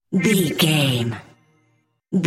Sound Effects
Atonal
funny
magical
mystical